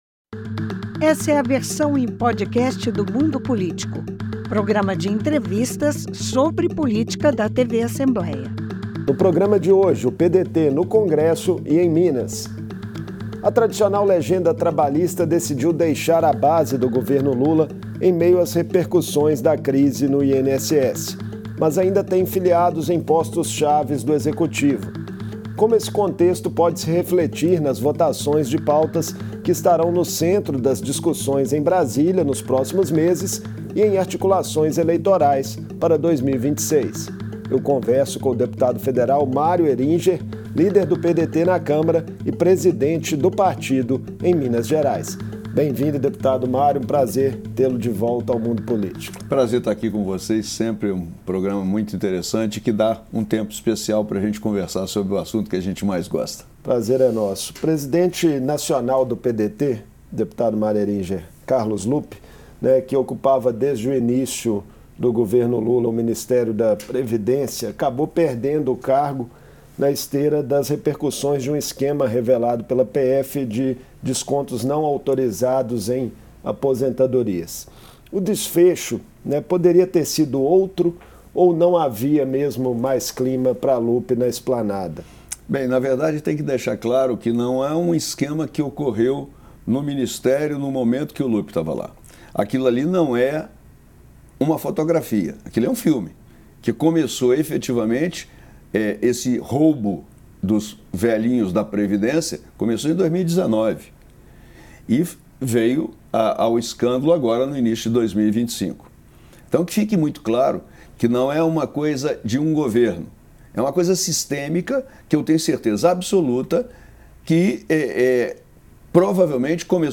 No início do mês, o PDT anunciou a decisão de se tornar um partido independente na Câmara. O presidente do PDT/MG, deputado federal Mario Heringer, falou ao Mundo Político sobre os rumos do partido na política nacional. Ele falou também sobre a relação com o governo federal, as eleições de 2026 e as pautas que estão em discussão no Congresso Nacional.